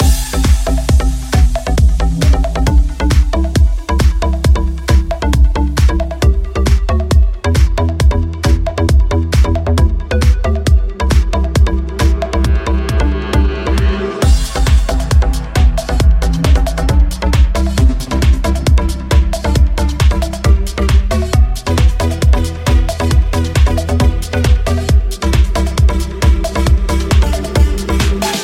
house
Genere: house, deep house, remix